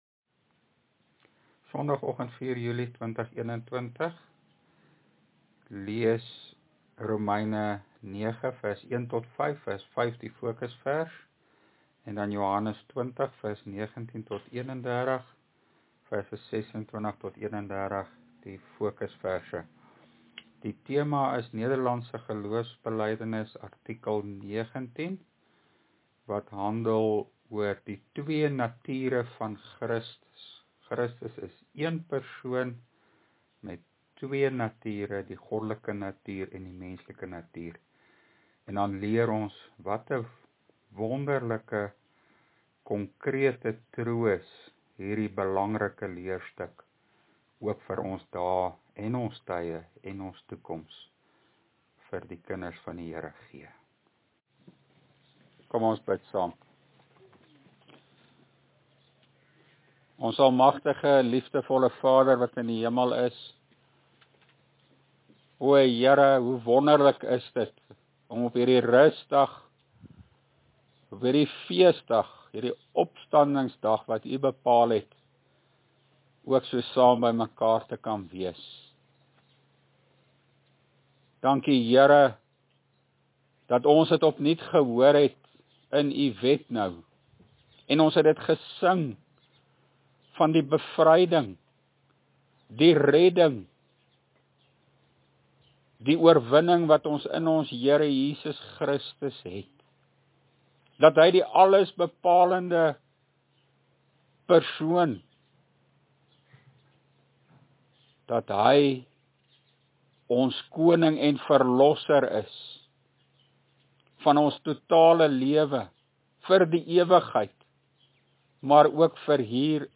LEERPREDIKING: NGB artikel 19